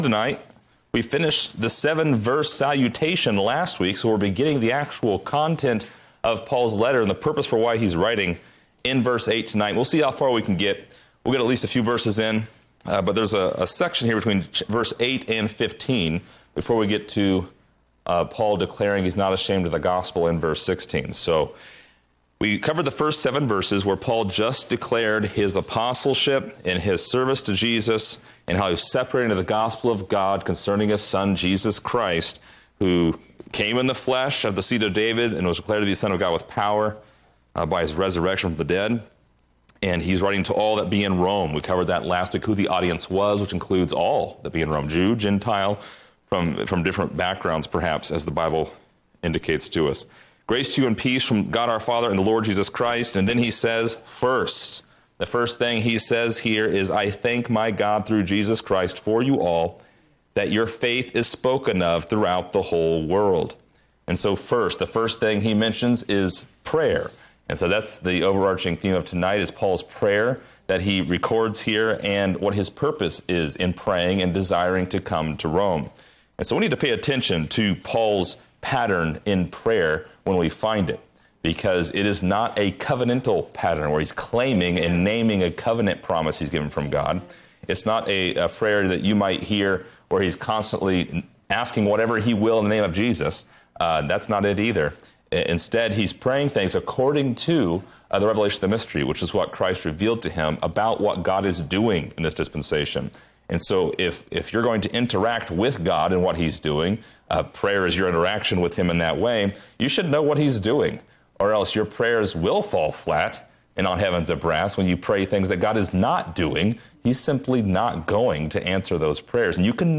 Description: This lesson is part 5 in a verse by verse study through Romans titled: First, Paul’s Prayer.